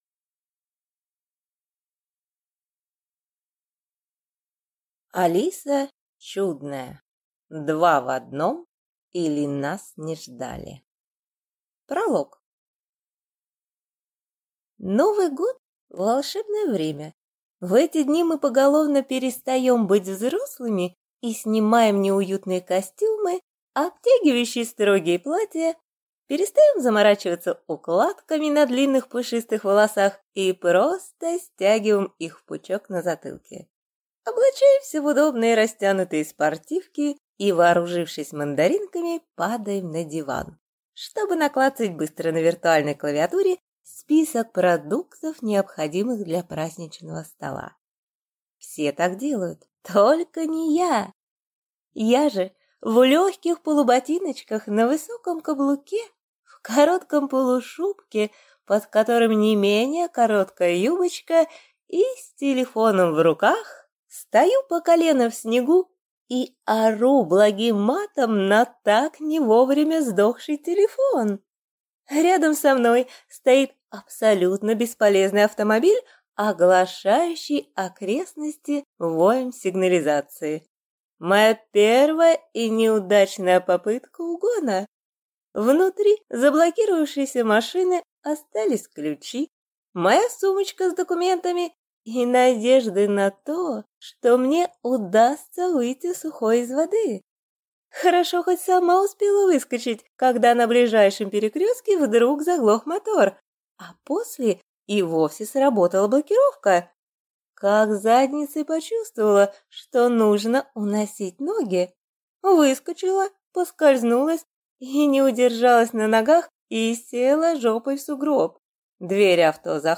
Аудиокнига 2 в 1, или Нас (не) ждали | Библиотека аудиокниг
Прослушать и бесплатно скачать фрагмент аудиокниги